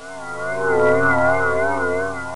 magnet.wav